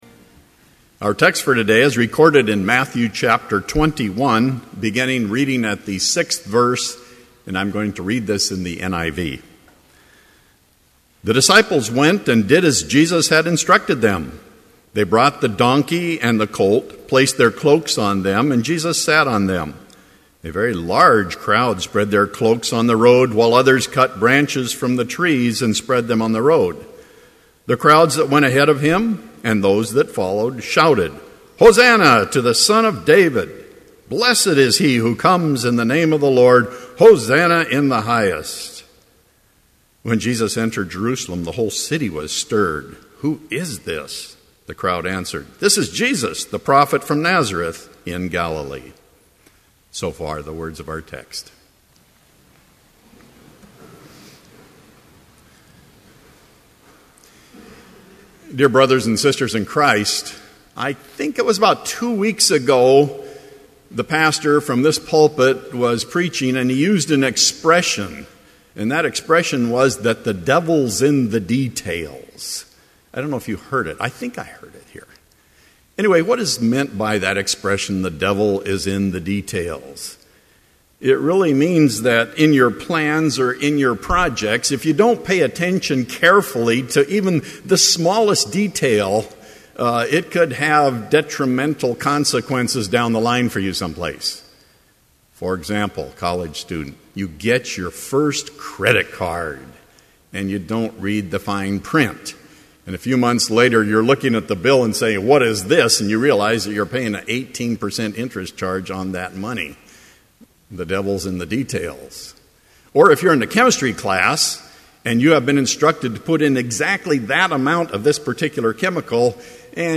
Complete Service
• Homily
This Chapel Service was held in Trinity Chapel at Bethany Lutheran College on Tuesday, April 3, 2012, at 10 a.m. Page and hymn numbers are from the Evangelical Lutheran Hymnary.